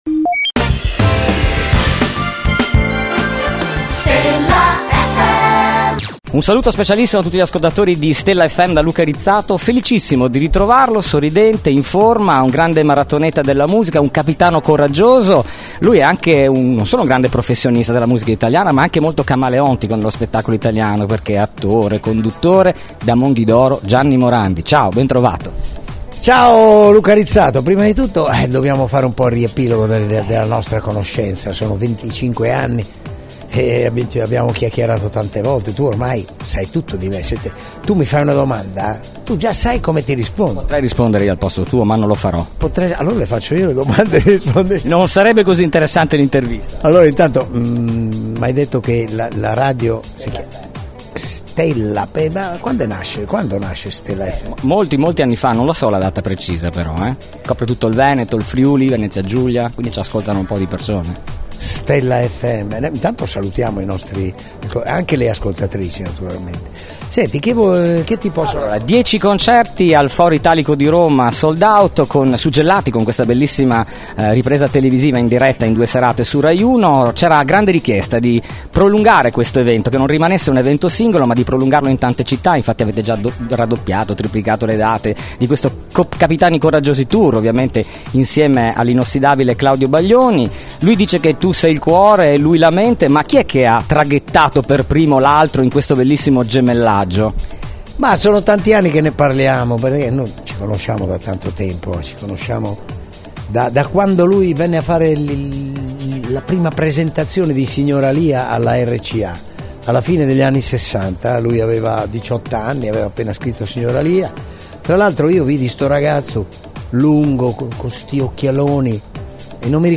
Intervista Gianni Morandi | Stella FM
Originale intervista con il simpatico cantante, cantautore, attore e conduttore televisivo.